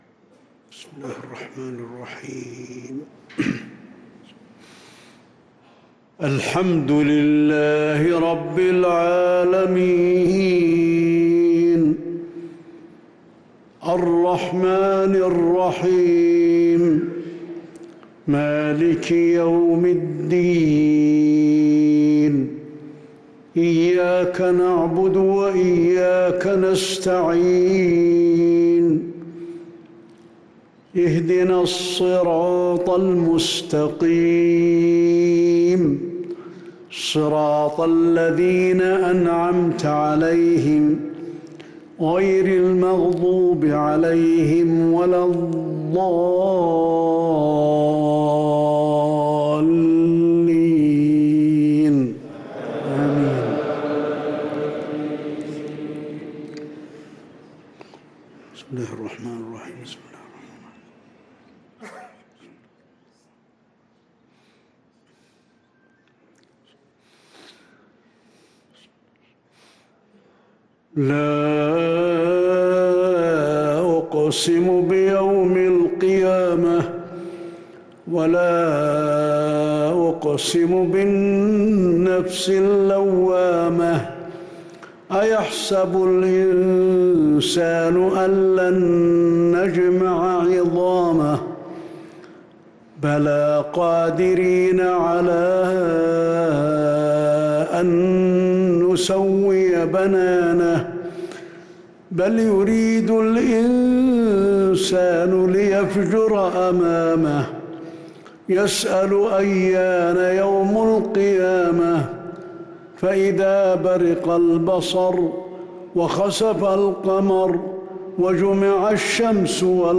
صلاة العشاء للقارئ علي الحذيفي 26 ربيع الأول 1444 هـ
تِلَاوَات الْحَرَمَيْن .